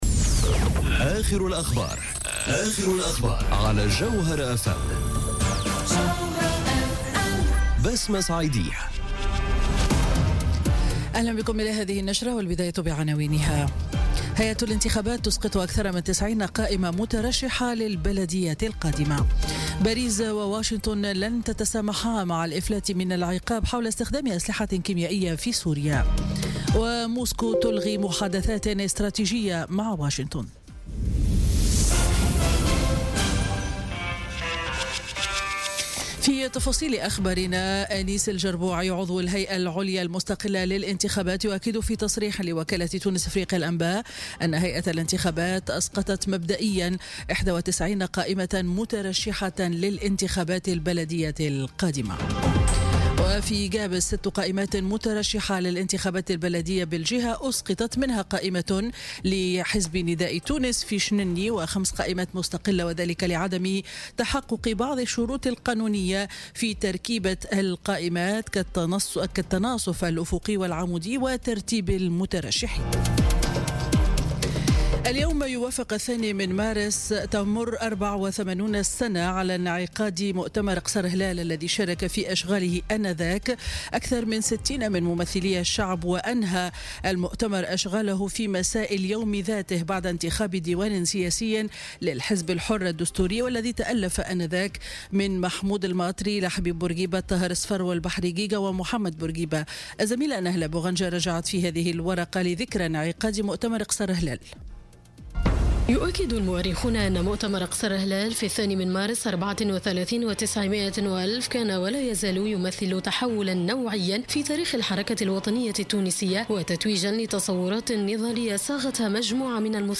نشرة أخبار منتصف النهار ليوم الجمعة 2 مارس 2018